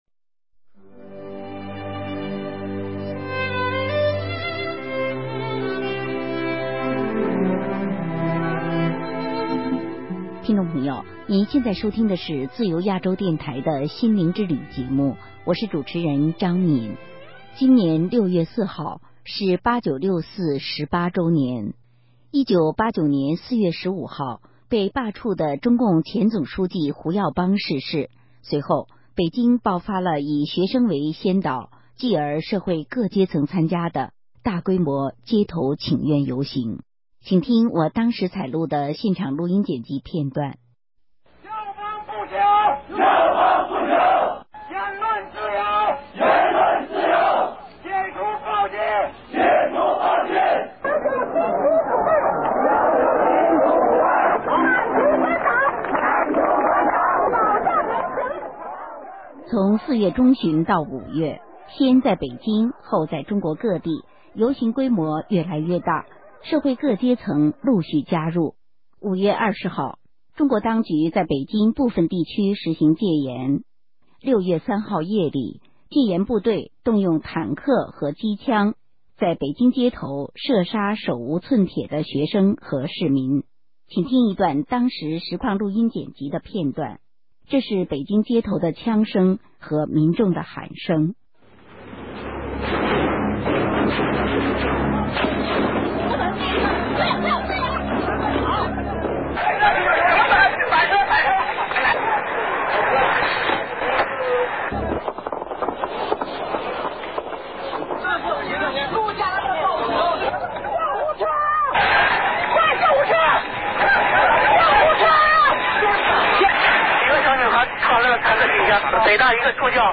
*十八年前北京街頭的聲音* 今年6月4日，是八九“六四”十八週年。